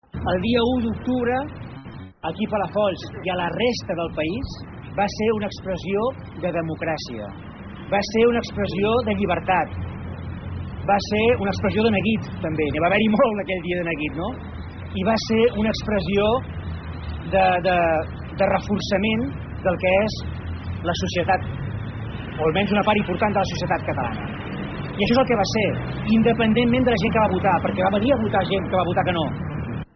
La inauguració del Passeig 1 d’octubre va reunir una 50a de persones en un acte que va comptar amb un parlament de l’alcalde i la lectura d’un manifest reivindicatiu de què va representar l’1 d’octubre. En aquest sentit Alemany va destacar que el referèndum va ser una expressió de democràcia i de llibertat.